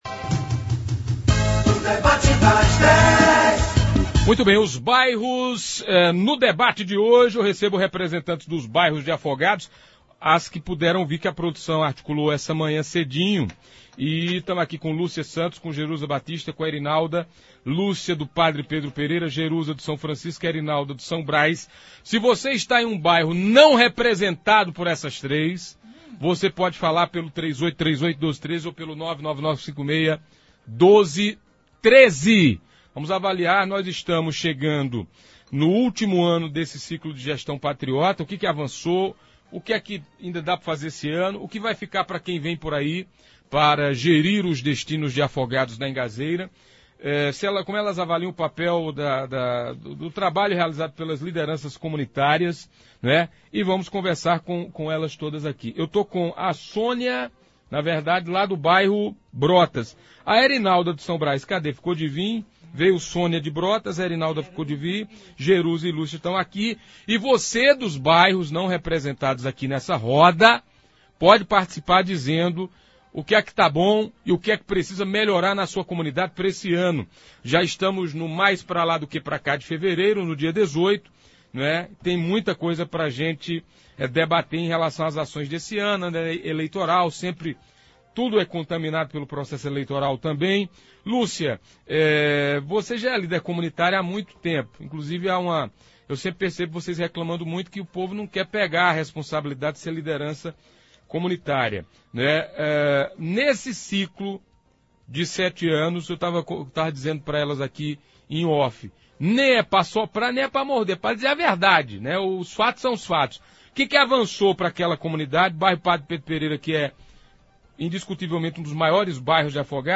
No Debate das Dez da Rádio Pajeú FM, desta terça-feira (18), representantes de bairros de Afogados da Ingazeira, avaliaram os avanços nesse ciclo de sete anos da gestão do prefeito José Patriota. Também falaram sobre o que ainda dá pra fazer neste ano, visto que ano eleitoral e a agenda de ações se torna mais curta e o que deve ficar para o próximo gestor.